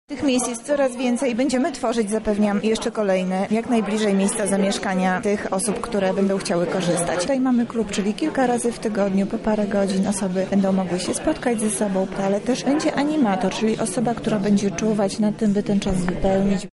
-To uzupełnienie oferty, którą tworzymy w naszym mieście – mówi Monika Lipińska, zastępca prezydenta Lublina